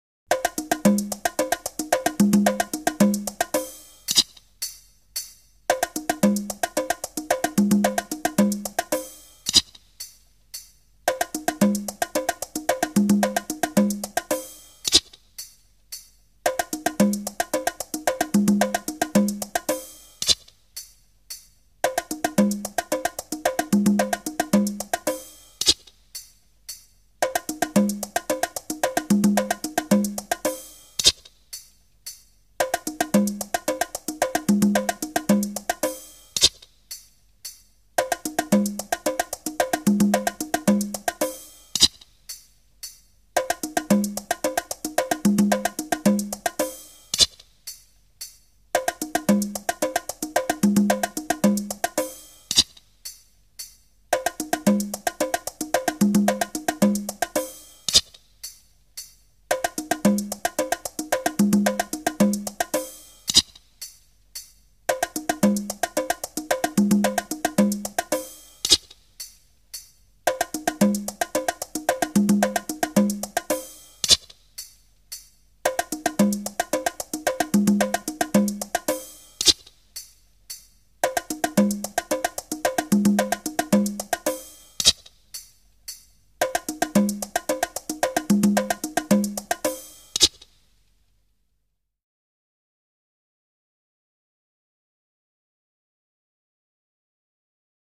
El fotògraf: A la següent pista d’audio de ritmes africans que us deixo se’ns ha colat un fotògraf, escolteu-la un parell de cops amb la canalla i que el trobin.